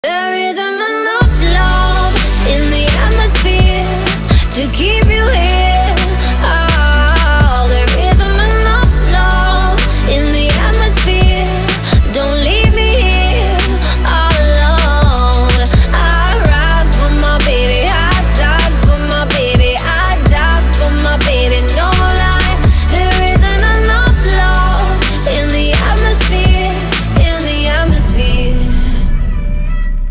• Качество: 320, Stereo
поп
женский вокал
RnB
vocal